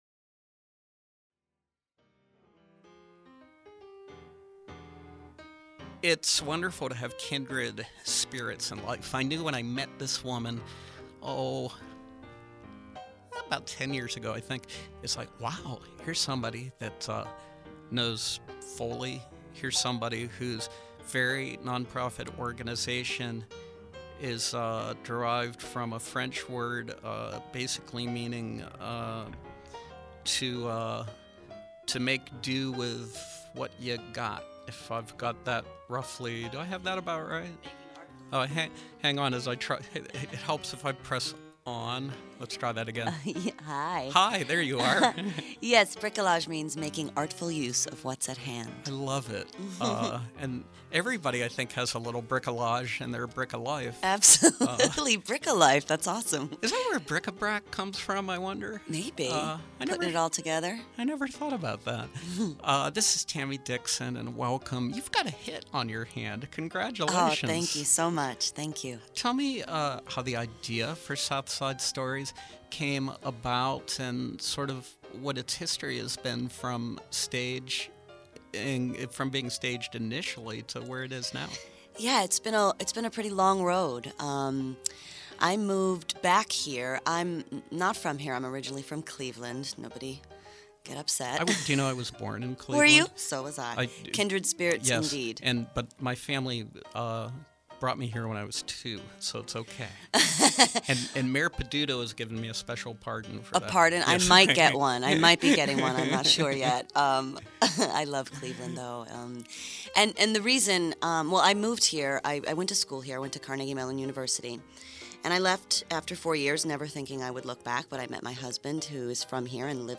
excerpt from her acclaimed one-woman play